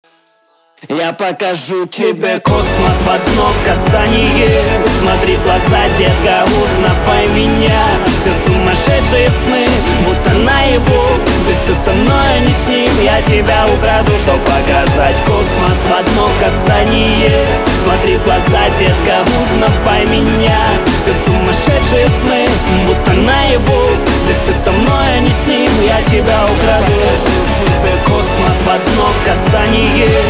• Качество: 320, Stereo
рэп